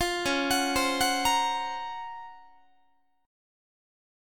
Listen to DbM13 strummed